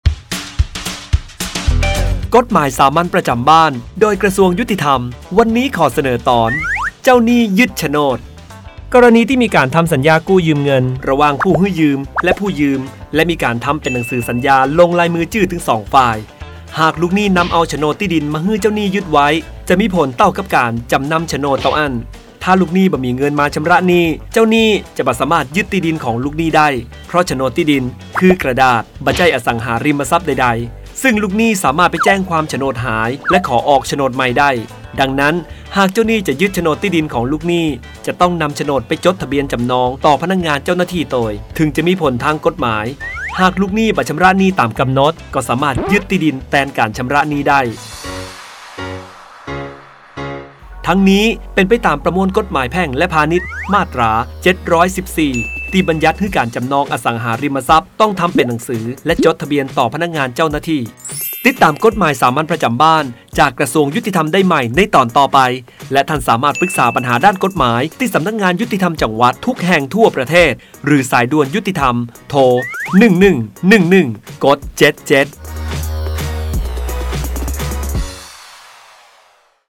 กฎหมายสามัญประจำบ้าน ฉบับภาษาท้องถิ่น ภาคเหนือ ตอนเจ้าหนี้ยึดโฉนด
ลักษณะของสื่อ :   บรรยาย, คลิปเสียง